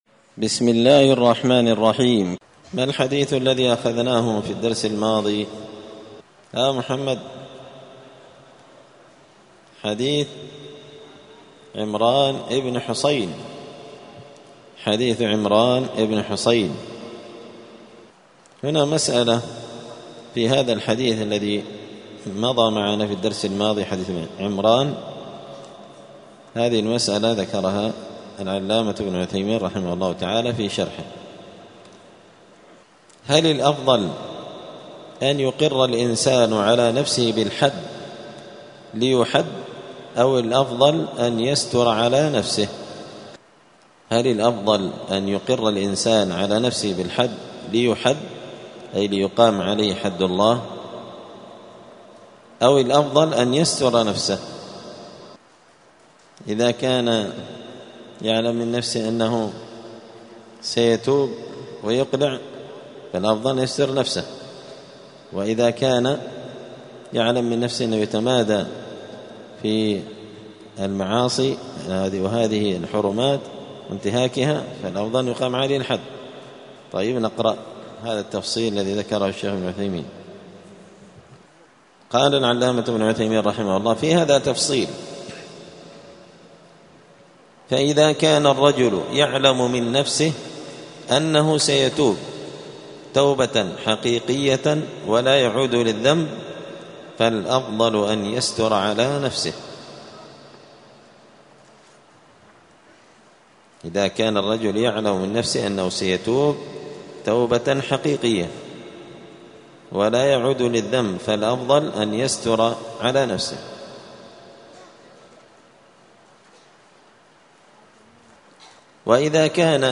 *الدرس التاسع (9) {باب إقامة الحد على الكافر إذا زنى}*